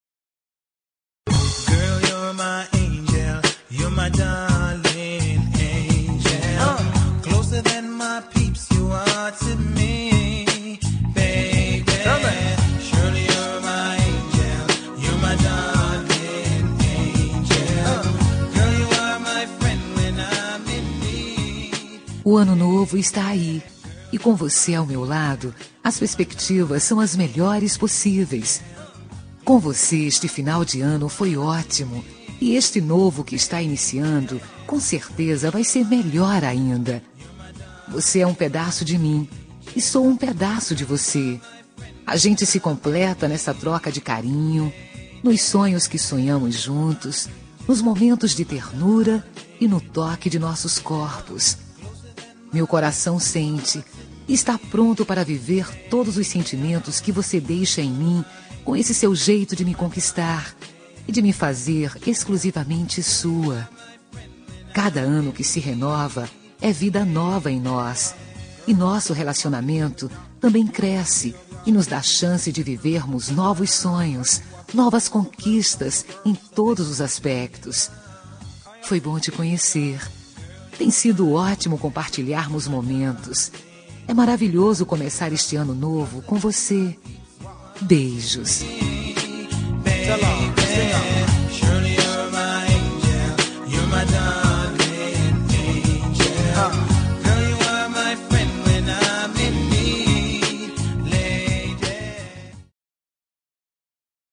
Ano Novo – Romântica – Voz Feminina – Cód: 6421